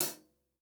TC Live HiHat 03.wav